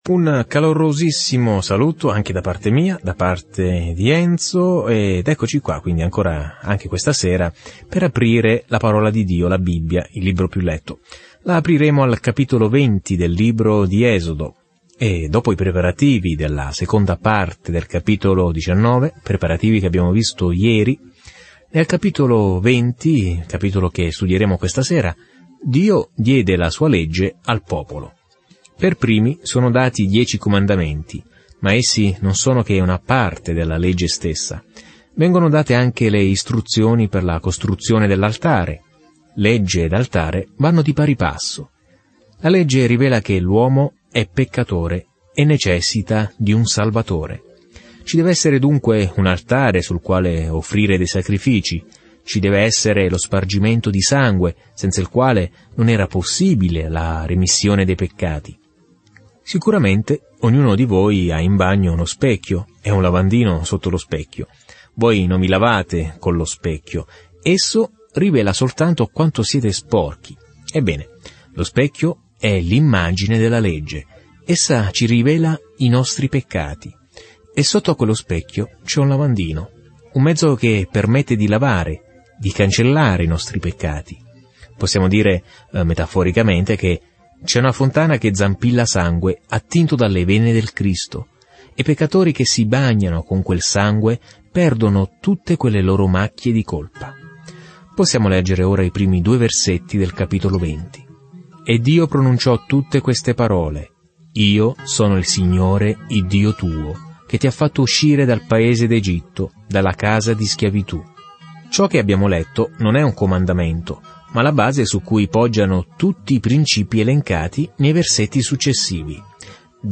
Scrittura Esodo 20:1-2 Giorno 21 Inizia questo Piano Giorno 23 Riguardo questo Piano L'Esodo ripercorre la fuga di Israele dalla schiavitù in Egitto e descrive tutto ciò che accadde lungo il percorso. Viaggia ogni giorno attraverso l'Esodo mentre ascolti lo studio audio e leggi versetti selezionati della parola di Dio.